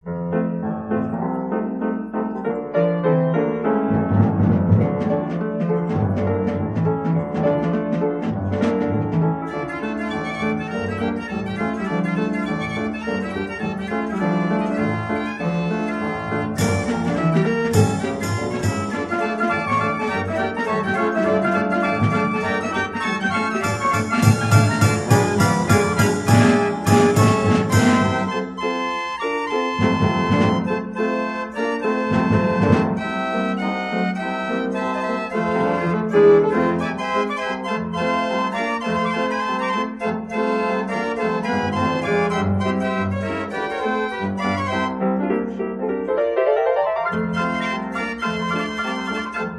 STYLE "H" SOLO ORCHESTRION VOL. 1,Vol 2